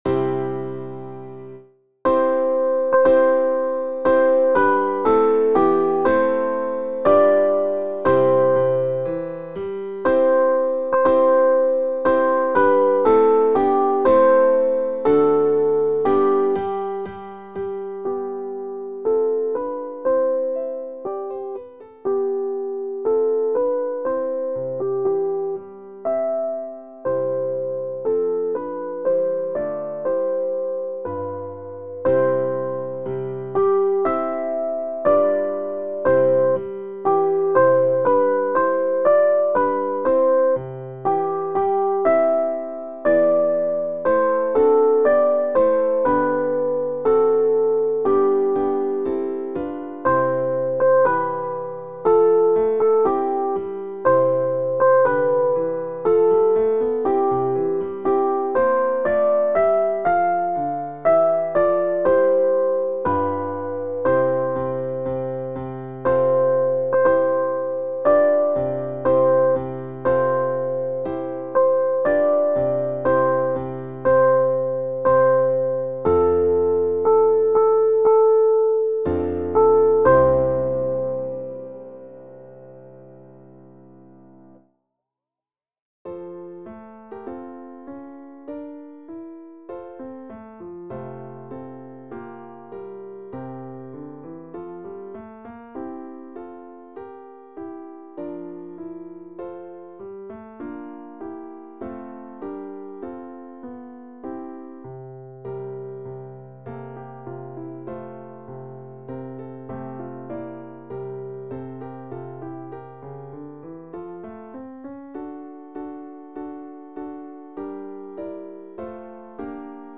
2_Gloria-Soprano.mp3